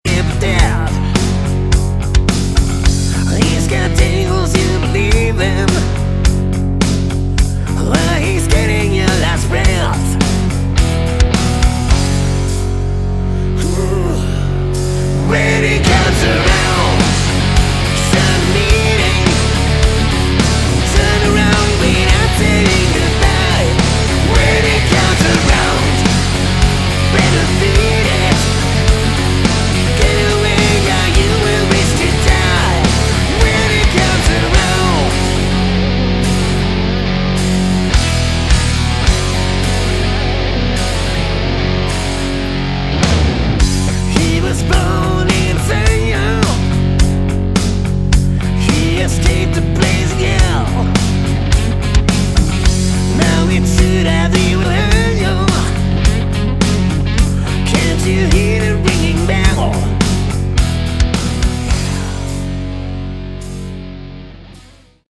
Category: Hard Rock
vocals
guitar
bass
drums
Great guitar solos in every song